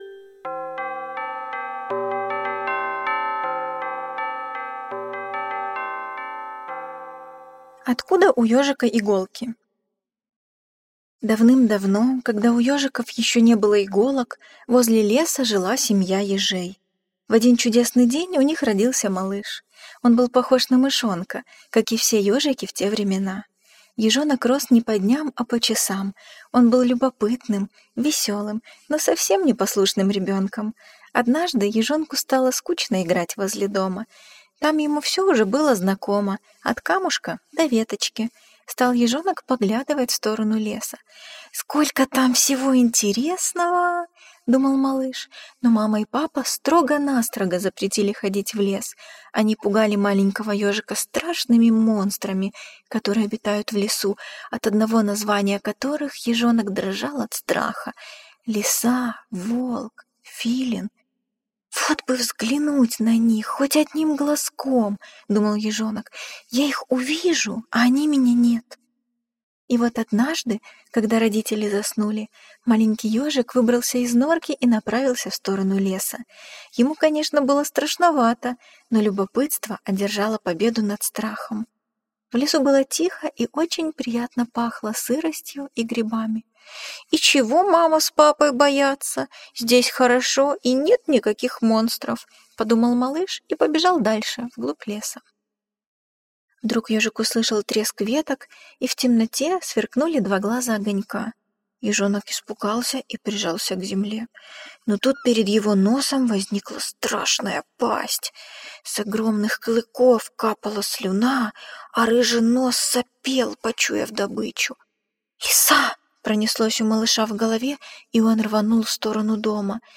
Откуда у ёжика иголки - аудиосказка - Потаповой - слушать онлайн